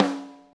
rim snare f.wav